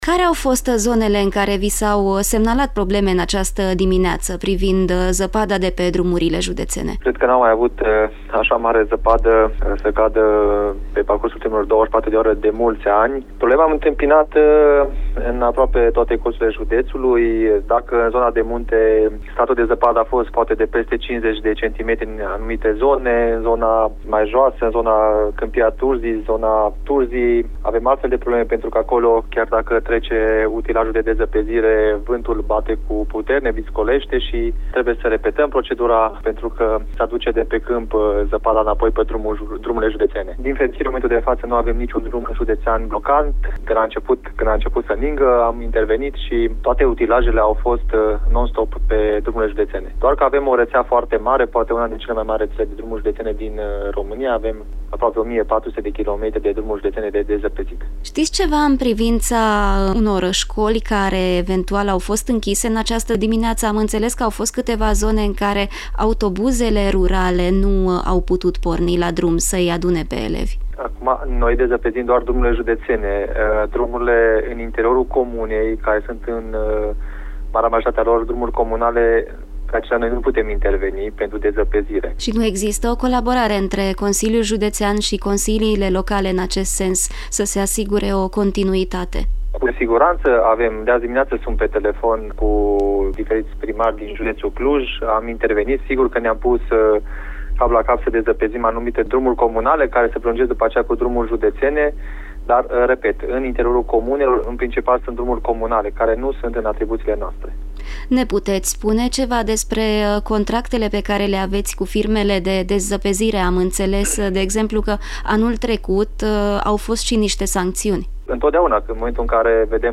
Vicepreședintele CJ Cluj, Radu Rațiu, spune că episodul de iarnă din aceste zile este unul dintre cele mai severe din ultimii ani.